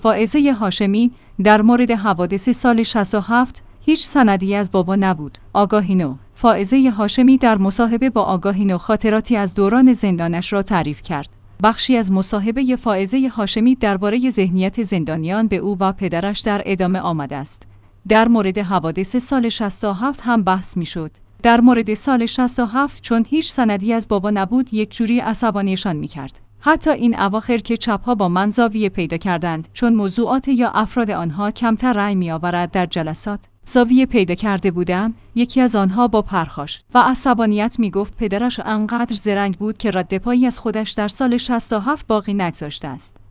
آگاهی نو/ فائزه هاشمی در مصاحبه با آگاهی نو خاطراتی از دوران زندانش را تعریف کرد.